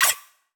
Robotic Button.wav